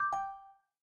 new-message.m4a